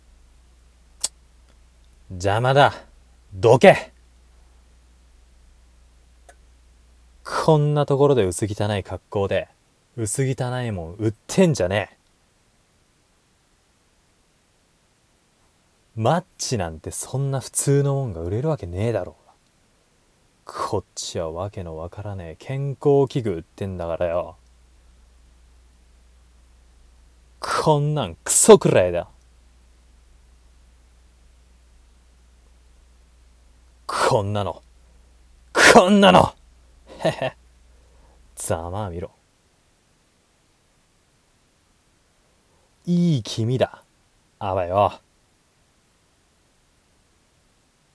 コラボ声劇1